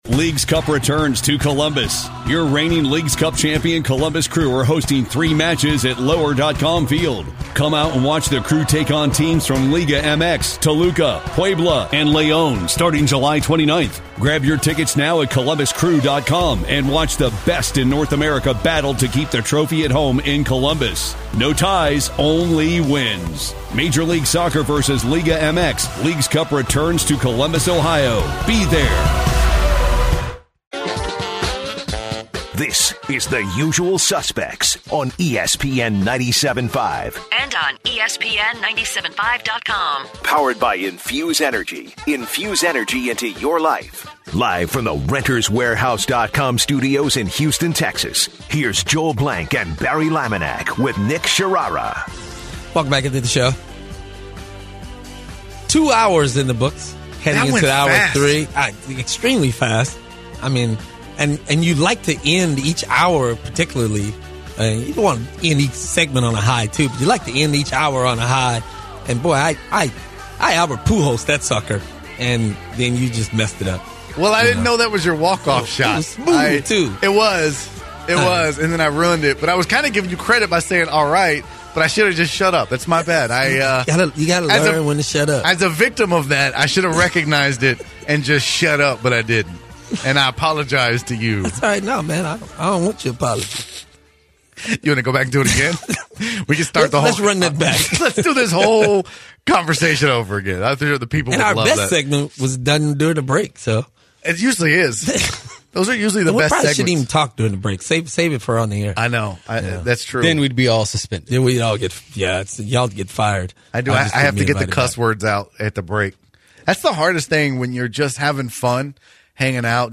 In hour three, the guys continue with James Harden and Kawhi Leonard. Then, the guys take listeners calls on James Harden and Kawhi Leonard comparisons. Also, the guys go into the Matt Harvey contraversy.